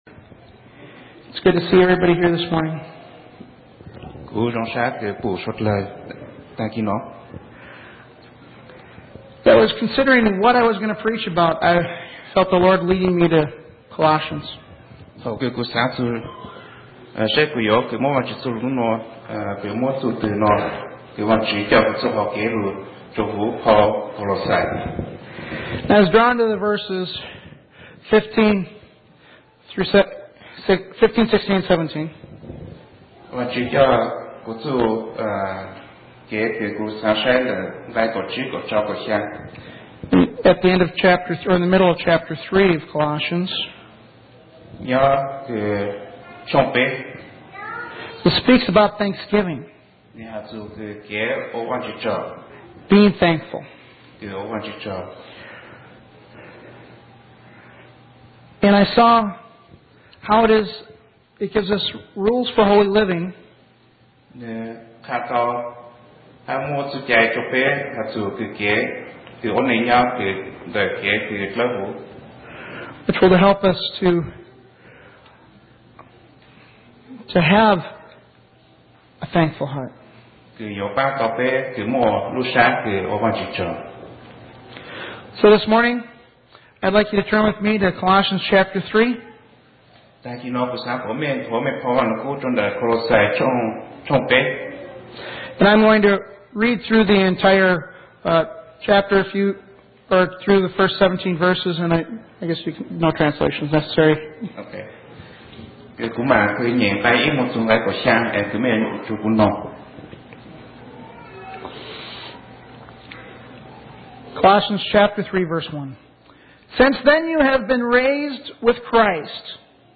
Joint Thanksgiving Service